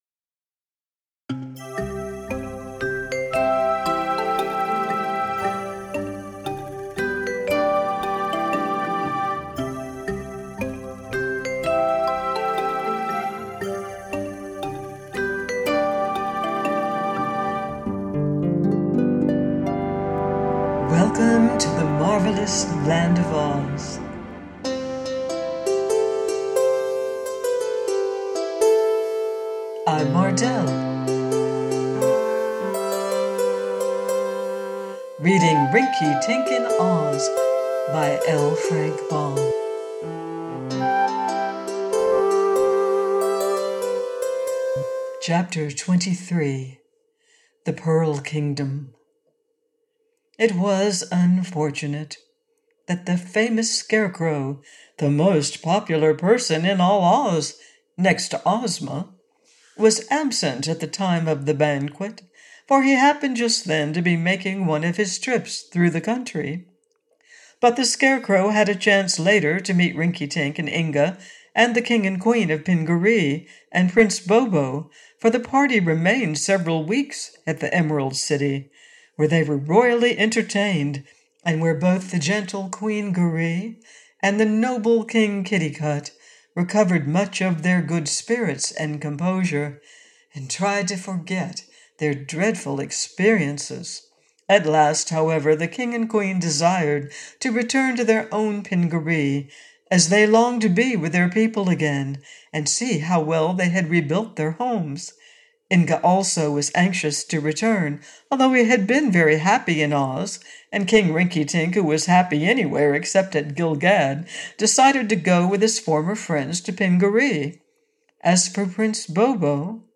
Rinkitink In Oz – by Frank L. Baum - AUDIOBOOK